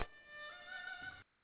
Dan Gao or Dan Ho Slightly bigger than the Dan Nhi with hemispherical resonance box made of coconut. Its sweet sonority resembles that of cello.